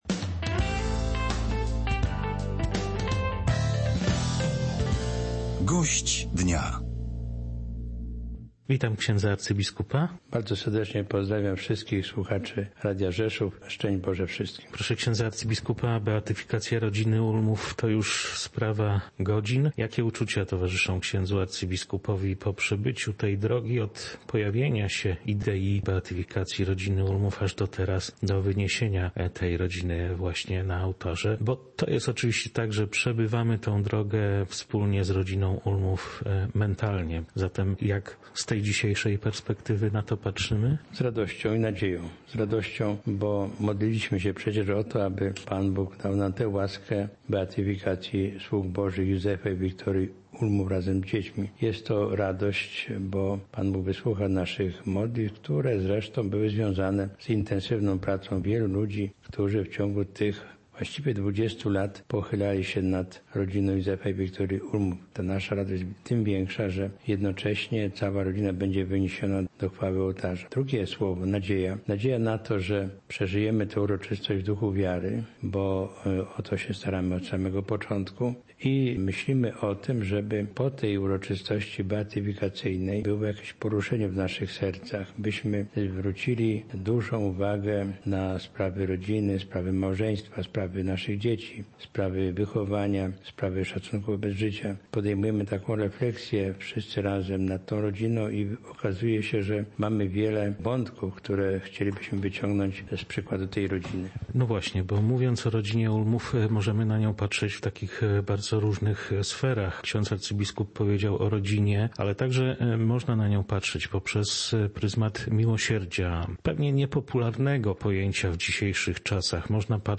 – podkreślił gość Polskiego Radia Rzeszów.